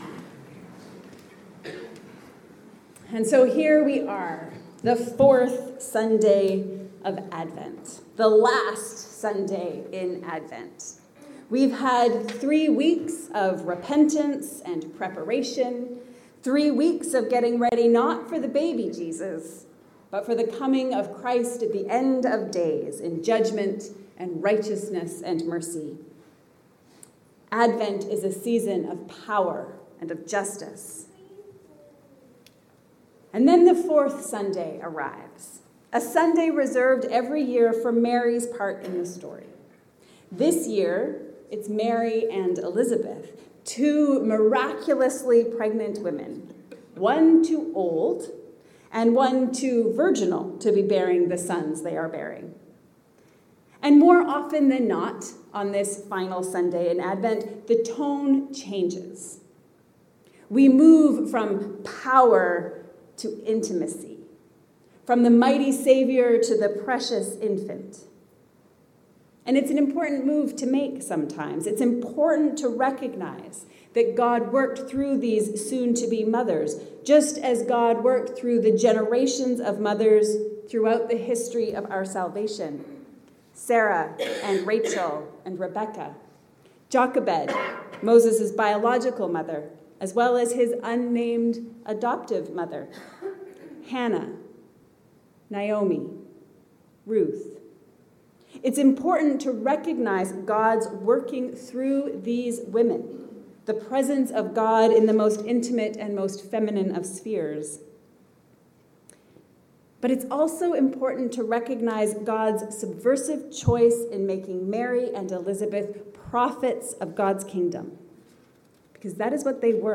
Blessed is she among women. A sermon for the 4th Sunday of Advent